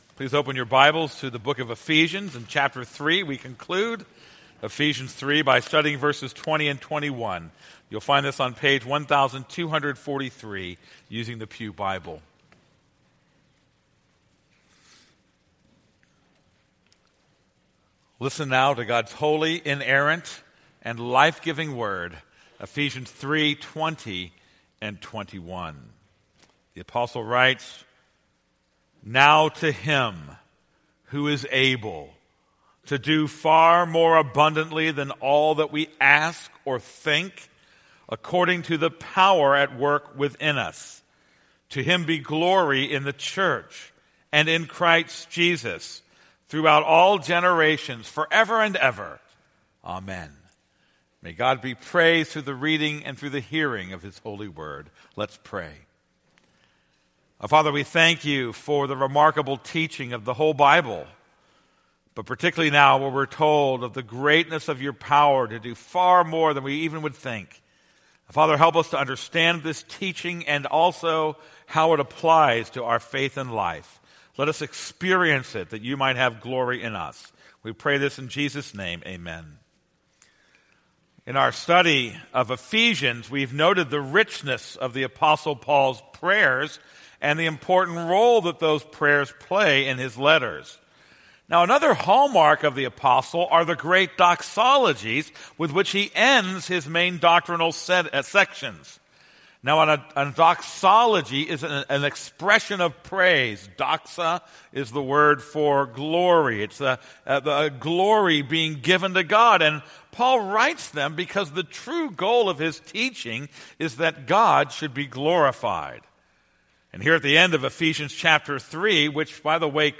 This is a sermon on Ephesians 3:20-21.